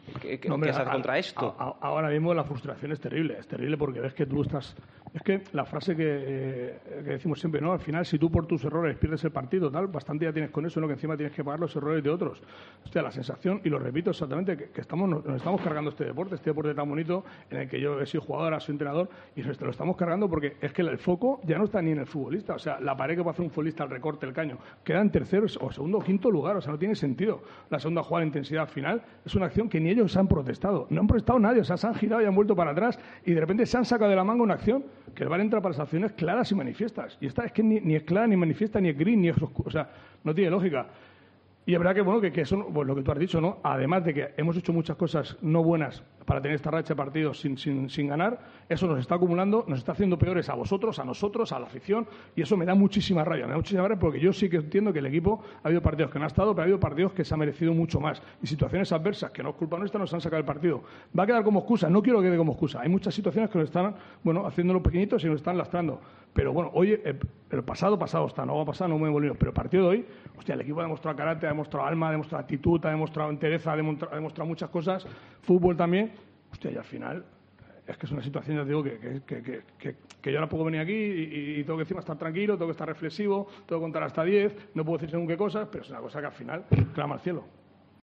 Un cabreo sonoro en la boca de Sergio González. Indignado, sin alzar la voz, con respeto pero con un profundo cabreo por una jugada, el penalti, que condicionó el resultado y el encuentro.